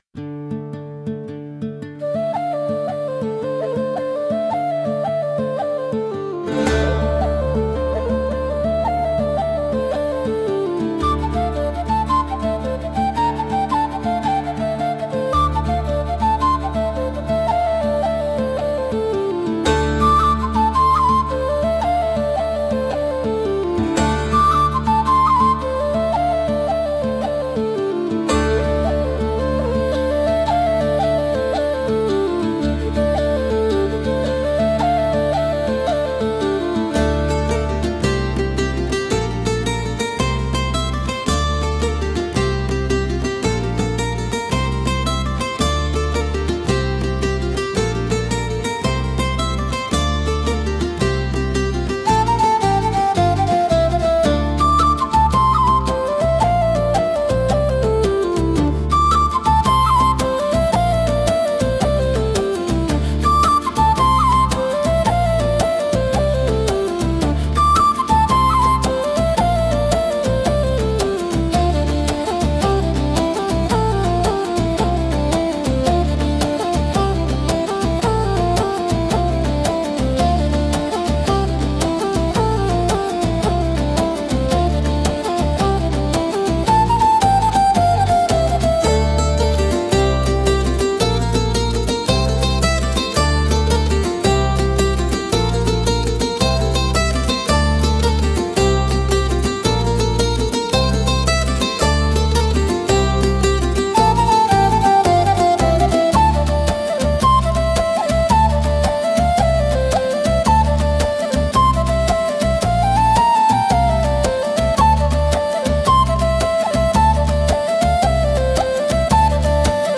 聴く人に親しみやすさと爽やかな感動を届ける民族的で情緒豊かなジャンルです。